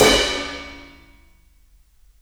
Index of /4 DRUM N BASS:JUNGLE BEATS/KIT SAMPLES/DRUM N BASS KIT 1
CRASH.wav